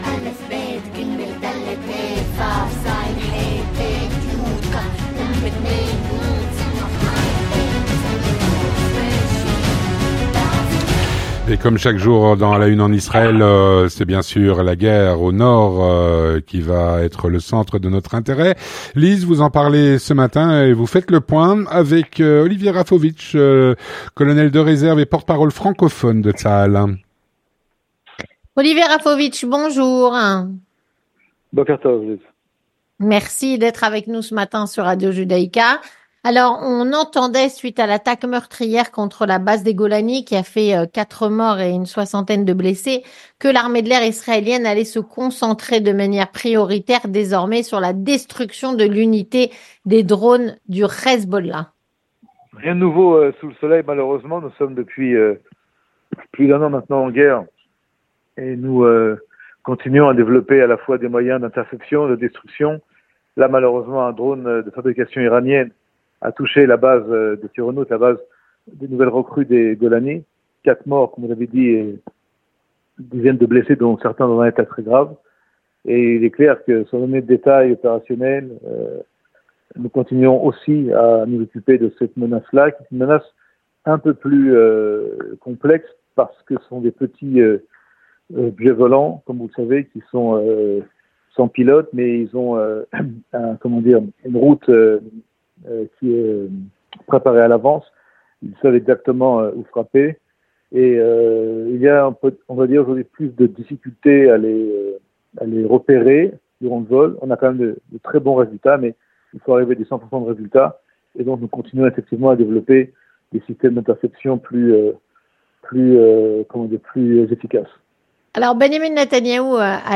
Avec Olivier Rafowicz, Colonel de Réserve et Porte-Parole francophone de Tsahal.